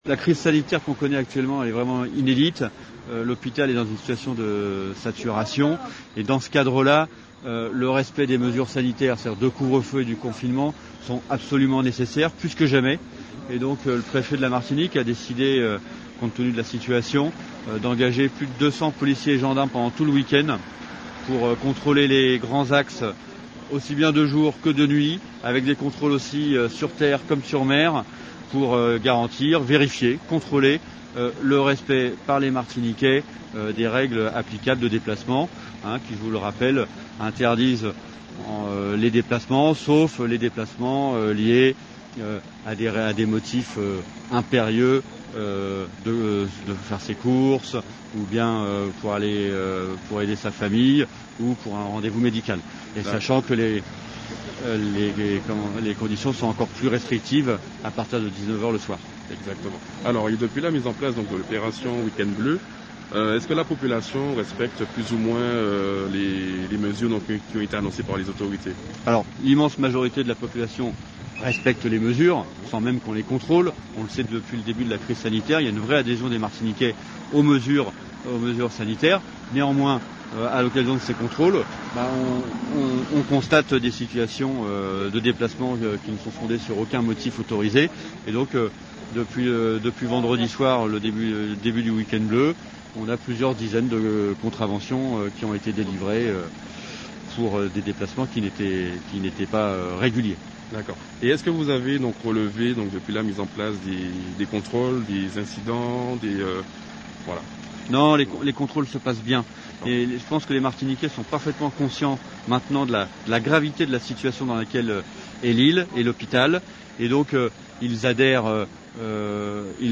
Les précisions d’Antoine Poussier, secrétaire général de la préfecture de Martinique.